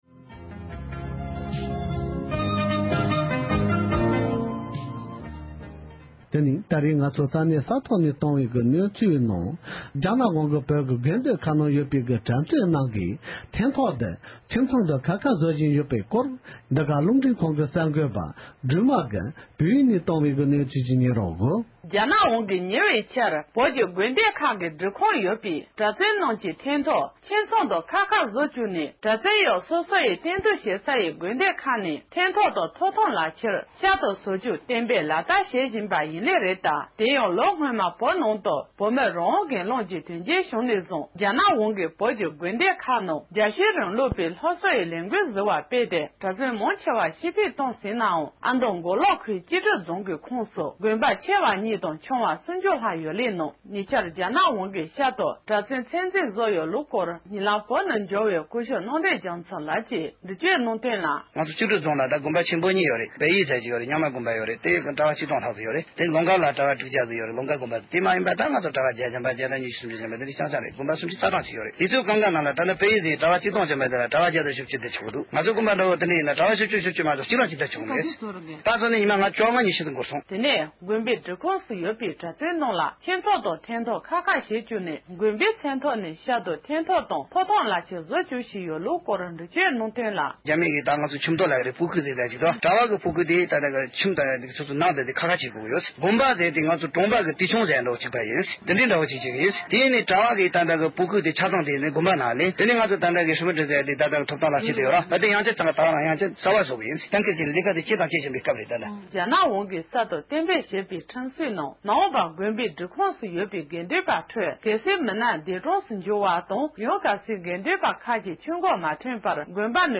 སྒྲ་ལྡན་གསར་འགྱུར། སྒྲ་ཕབ་ལེན།
ཉེ་ཆར་བོད་ནས་བཙན་བྱོལ་དུ་འབྱོར་བའི་བོད་མི་ཞིག་གིས།